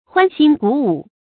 歡忻鼓舞 注音： ㄏㄨㄢ ㄒㄧㄣ ㄍㄨˇ ㄨˇ 讀音讀法： 意思解釋： 形容歡樂振奮，感情激動。